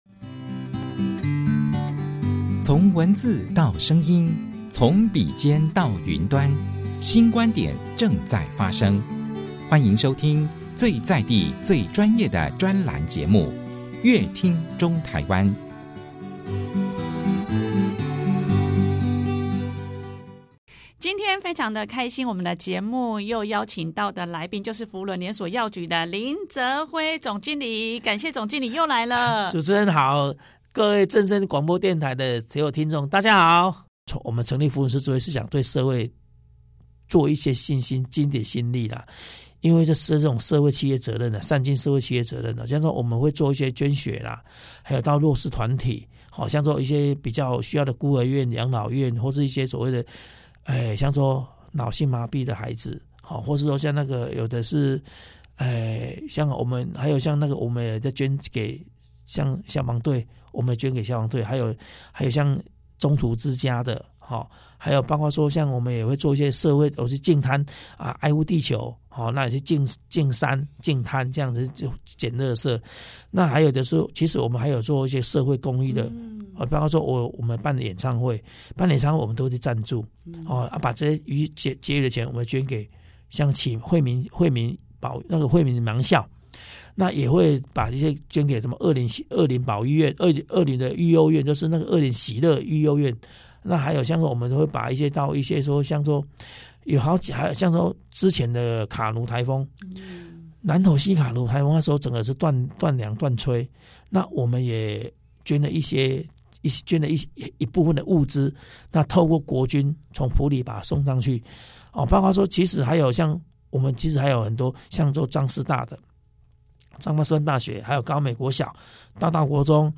更多精彩的專訪內容請鎖定本集節目。